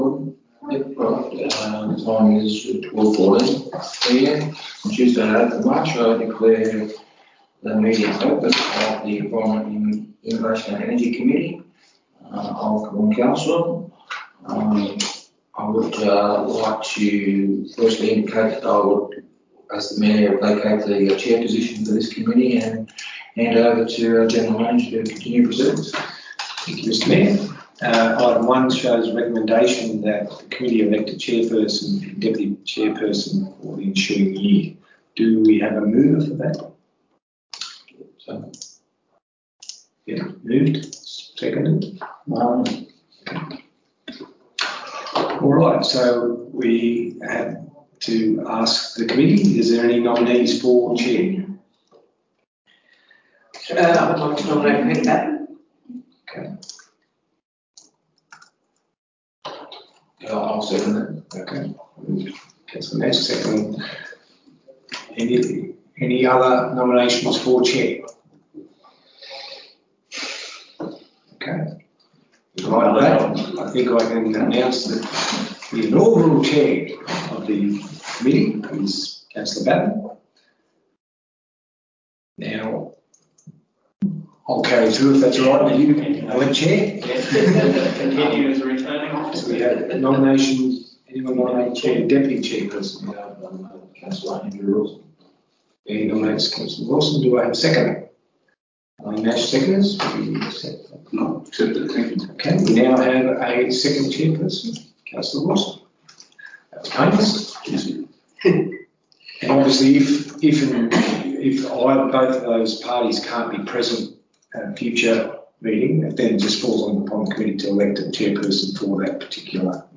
This is an ordinary meeting of Council's Environment, Innovation & Energy Committee